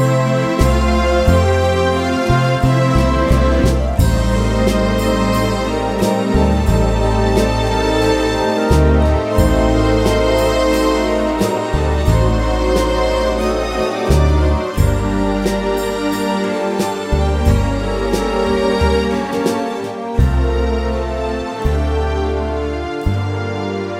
Live Version Jazz / Swing 4:03 Buy £1.50